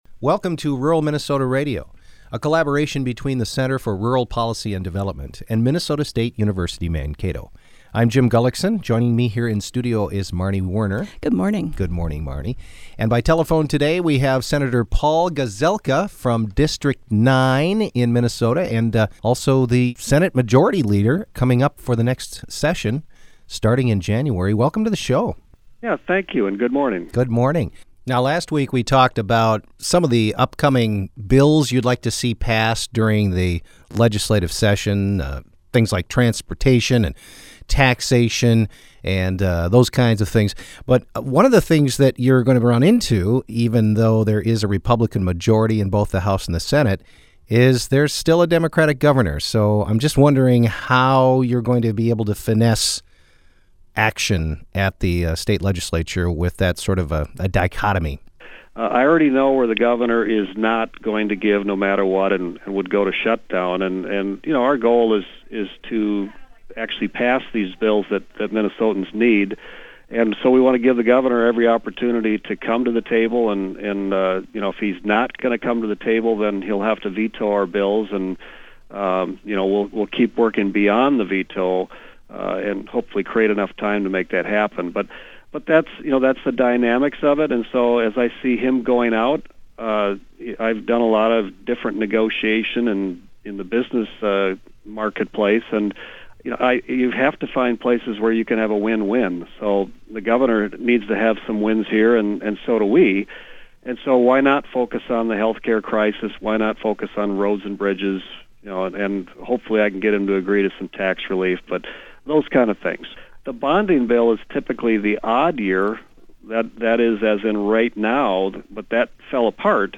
Part 2 of our conversation with income Senate Majority Leader Paul Gazelka on the upcoming state legislative session.